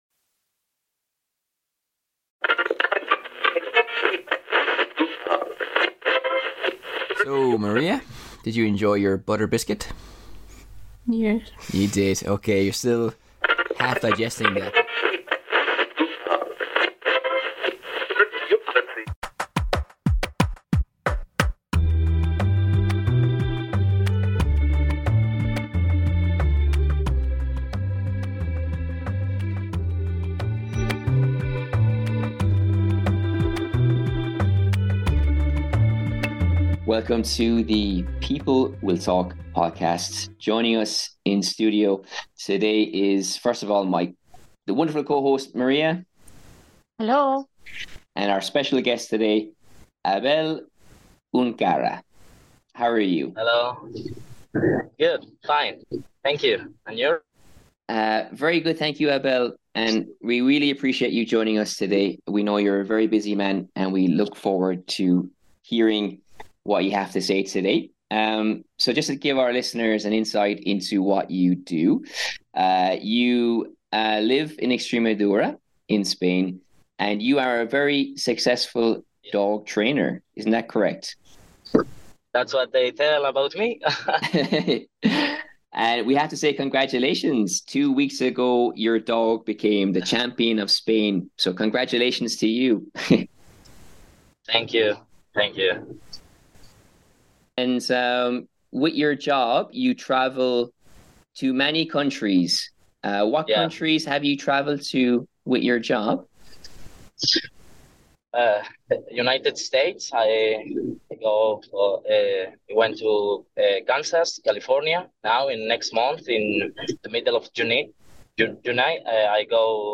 Interview with a Celebrated Dog Trainer